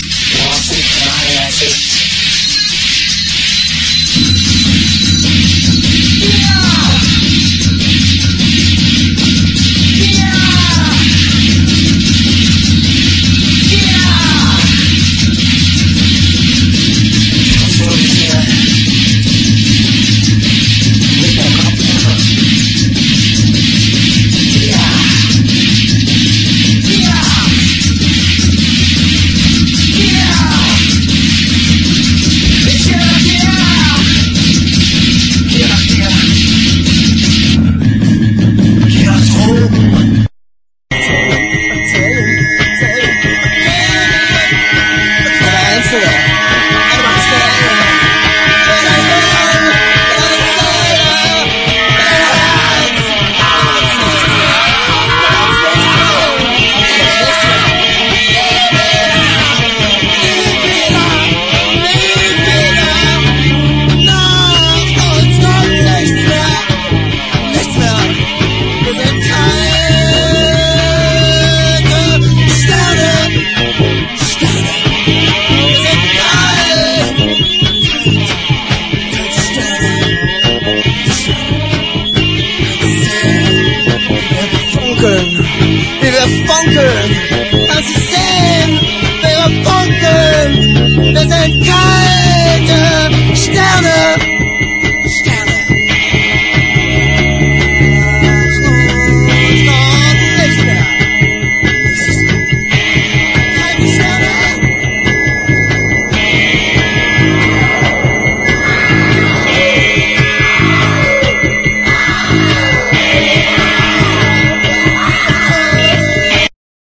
¥1,980 (税込) ROCK / 80'S/NEW WAVE.
ミニマルなスピリチュアル・エスノ・グルーヴ人気盤！